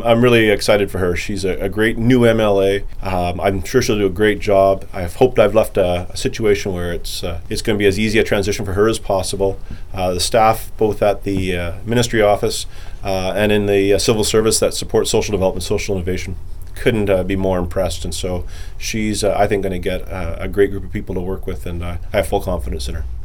Goat News spoke with McRae to get his thoughts on his successor.